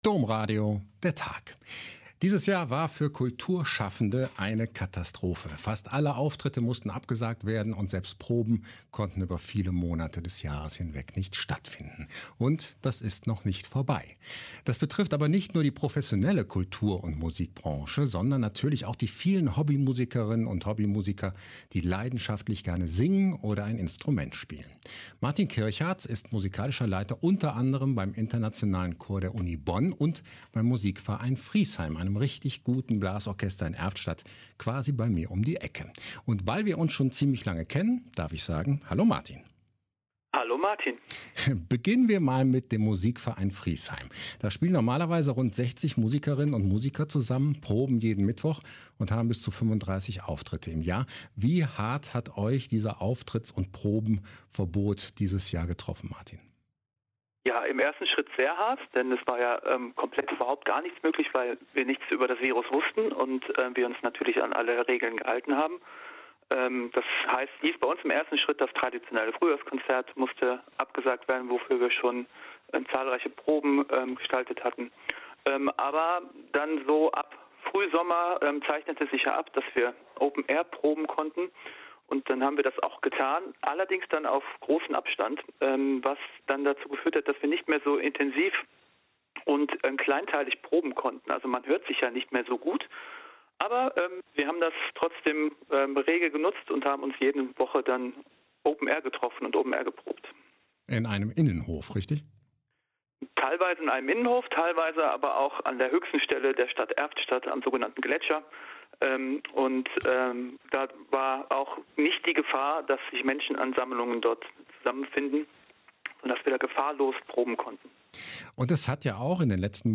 Ein Interview
Daher wurden diese aus der Aufnahme entfernt.)